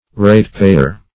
Search Result for " ratepayer" : Wordnet 3.0 NOUN (1) 1. a person who pays local rates (especially a householder) ; The Collaborative International Dictionary of English v.0.48: Ratepayer \Rate"pay`er\ (-p[=a]`[~e]r), n. One who pays rates or taxes.